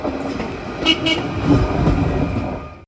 IR4 el-horn (direkte lydlink)
ir4horn.mp3